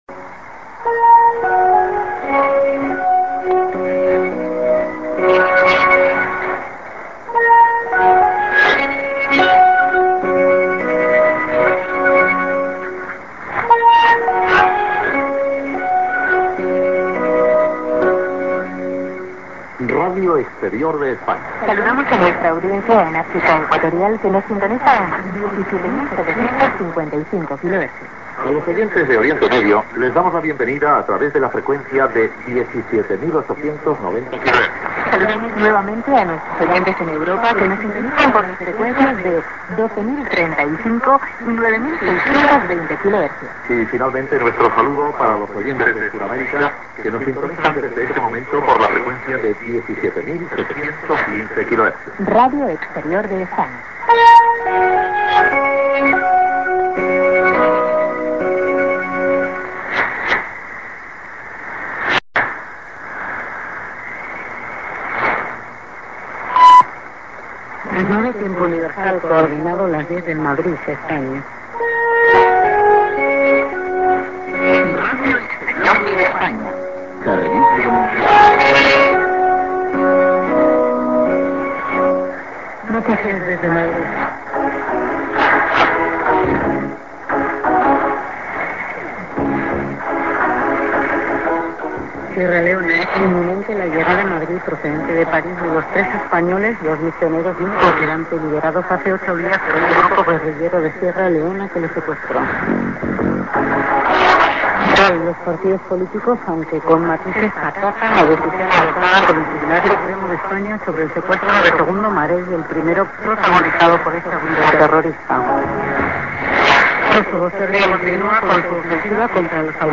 St. IS->ID+SKJ(man+women)->IS->TS->ID(women)+IS+ID(women)->